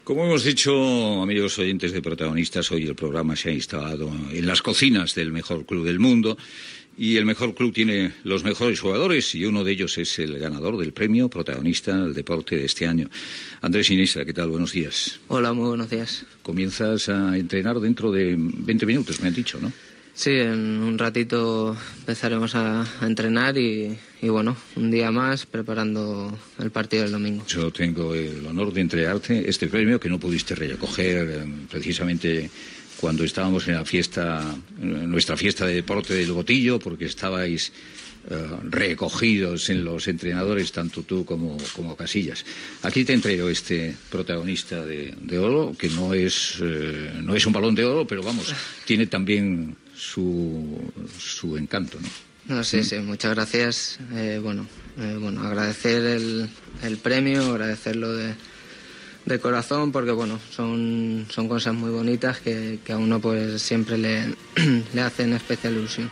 Fragment d'una entrevista al futbolista Andrés Iniesta. Lliurament del premi "Protagonista del deporte"
Info-entreteniment